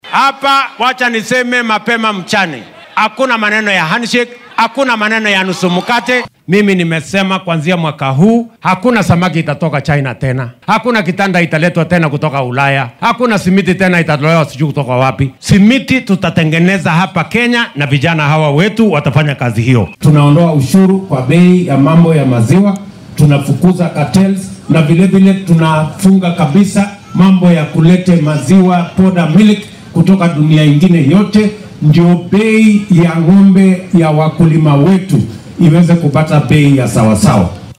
William Ruto ayaa xilli uu mashaariic horumarineed xarigga uga jarayay ismaamulka Nyeri ee gobolka bartamaha Kenya waxaa uu dhanka kale difaacay xeerka maaliyadda ee sanadkan oo uu sheegay in canshuurta dheeraadka ee la uruurinaya ay wadanka u horseedi doonto kobac dhaqaale.